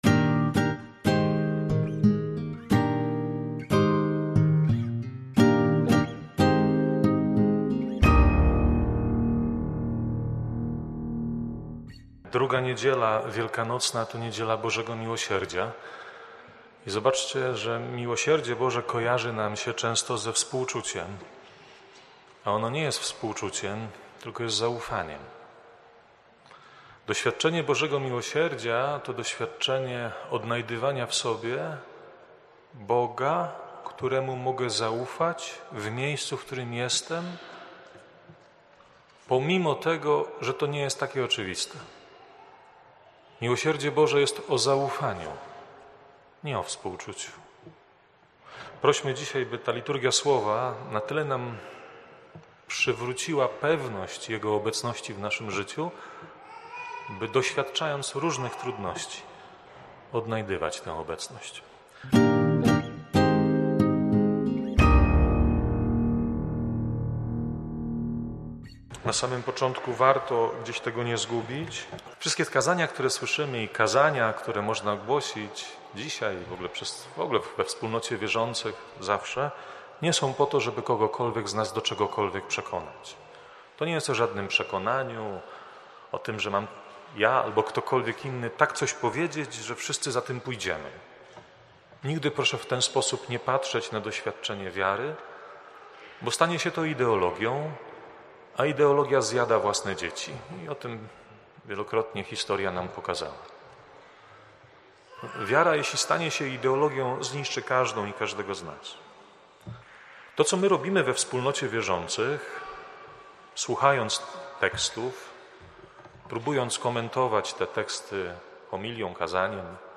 kazania.
wprowadzenie do Liturgii, oraz kazanie: